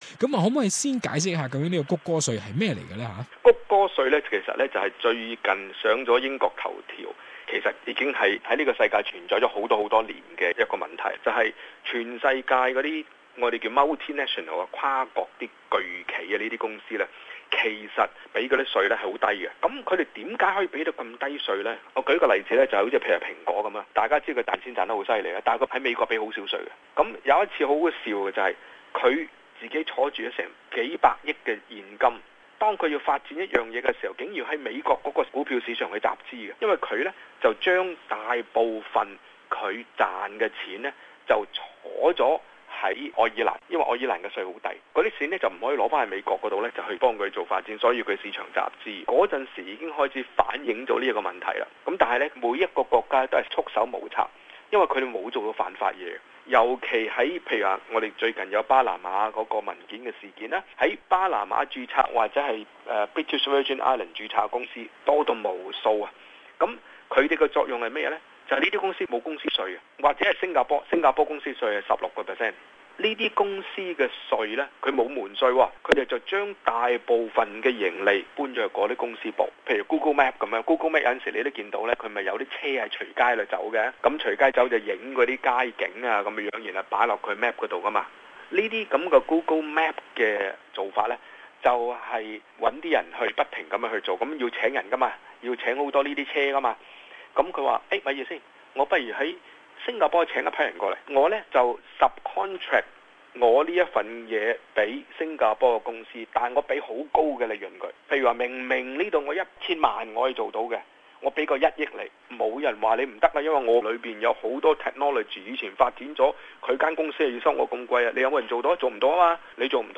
What is Google tax? how does it work? What impact will bring to the local market and Australian economy? In an interview